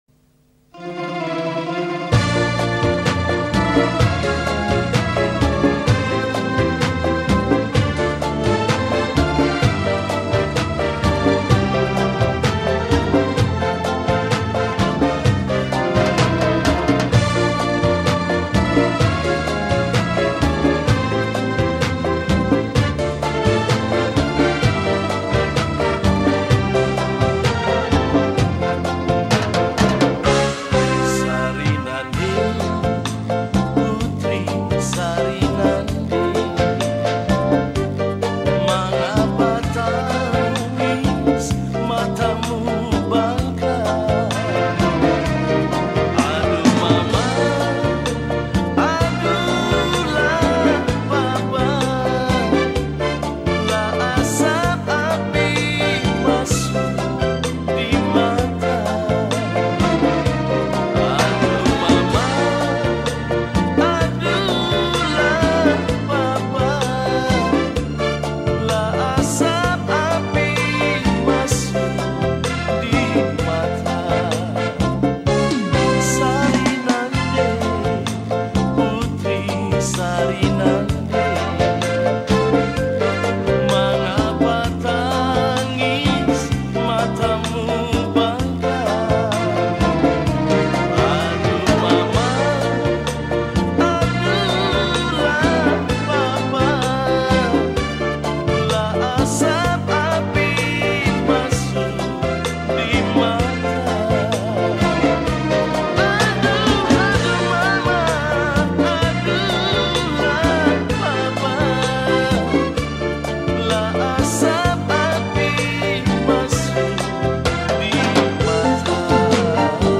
Lagu Daerah Maluku Indonesia